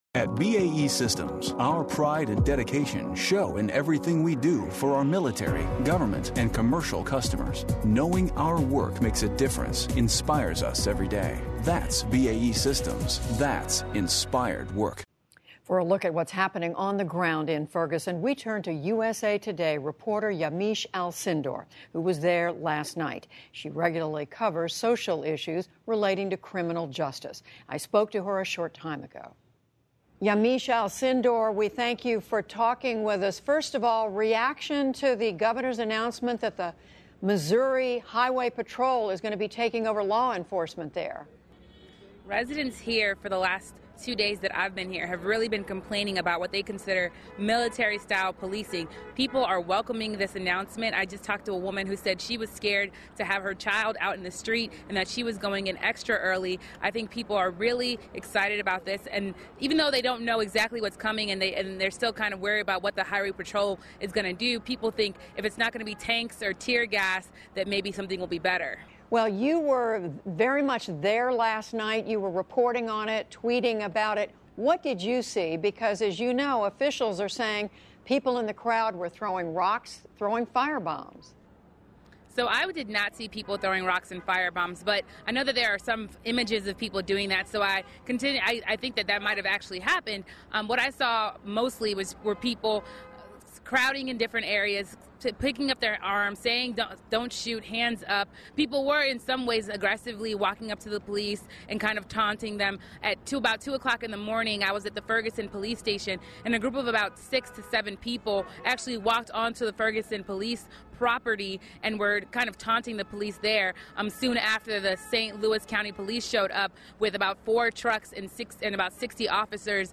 Judy Woodruff talks to Yamiche Alcindor of USA Today in for a closer look at the drama unfolding over the police killing of Michael Brown, as well as local reaction to the governor’s order for State Highway Patrol to take over security.